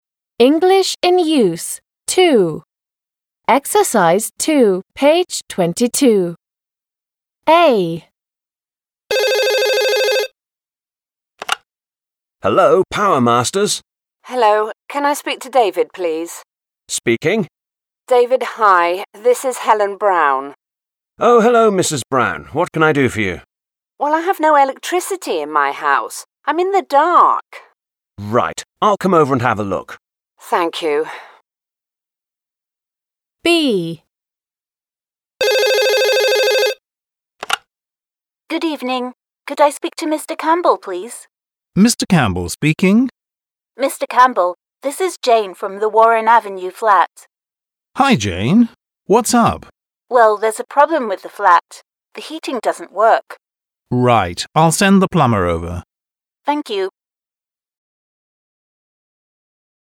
They come from two telephone conversations.